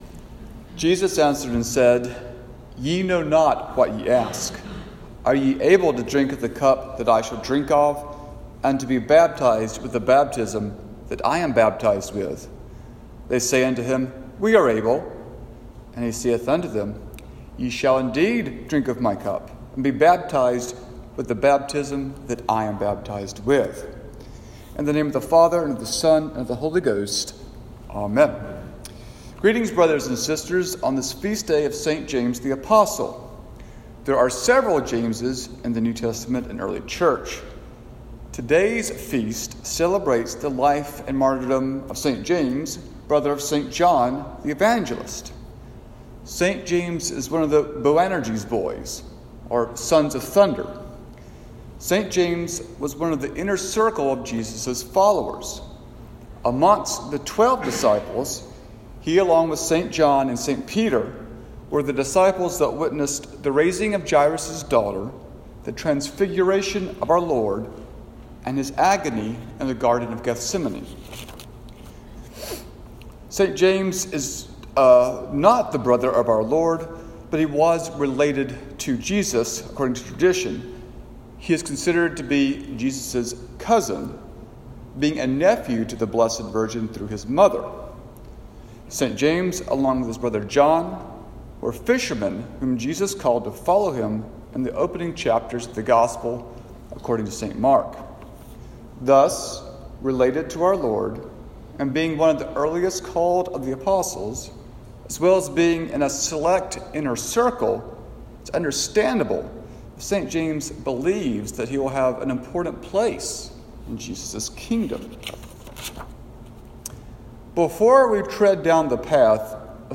Sermon for St. James Day